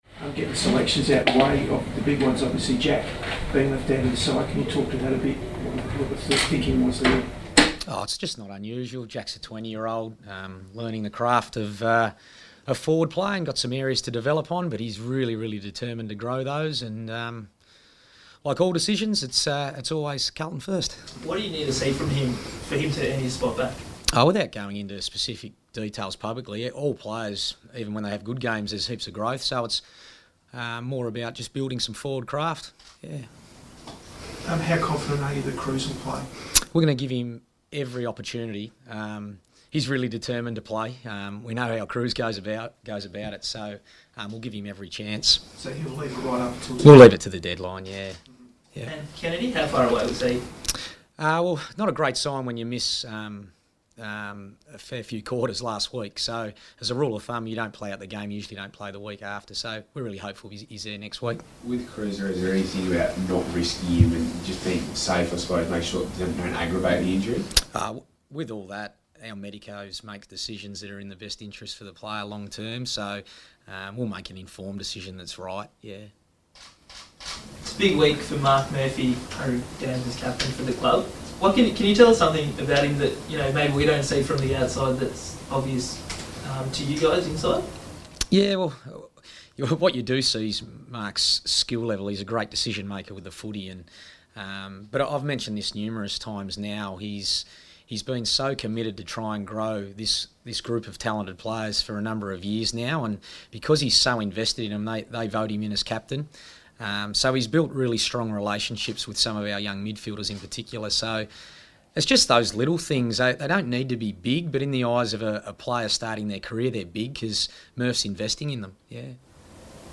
Brendon Bolton press conference | March 30
Carlton coach Brendon Bolton fronts the media on the eve of Carlton's Round 2 clash against the Suns.